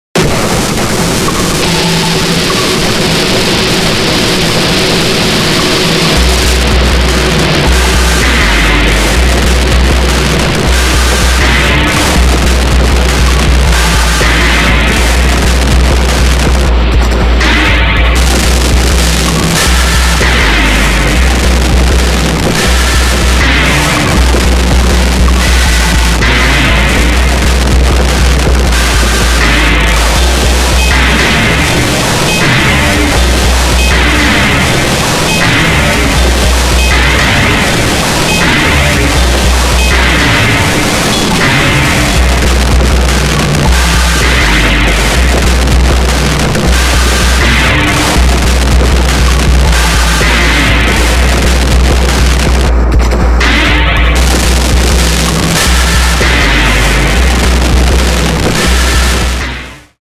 ノイズにまみれた打撃音と、不気味なリフレインで舞う
ジャパニーズ・エクスペリメンタル・ビートの暗黒面。
横浜在住 トラックメイカー/DJ/絵描き
Genre:　 JAPANESE EXPERIMENTAL BEAT
Style:　Experimental, Abstract, Electronica, Hip hop